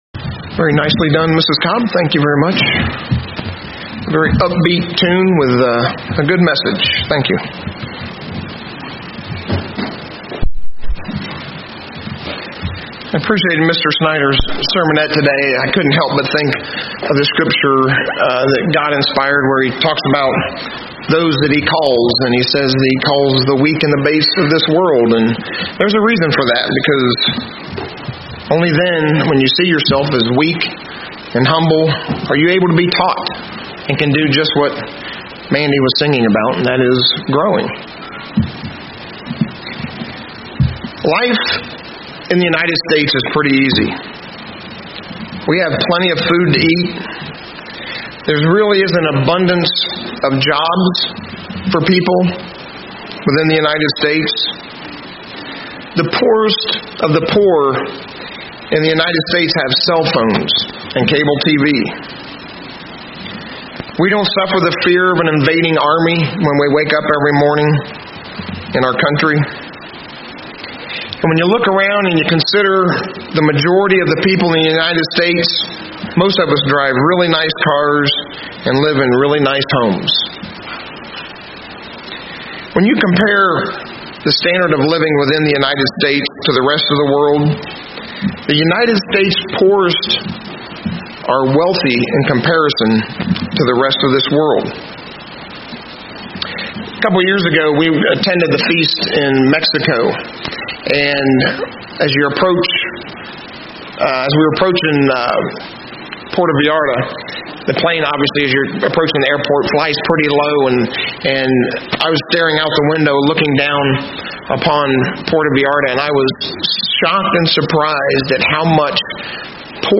Sabbath Services assembly Notes We live in one of the wealthiest countries in the world.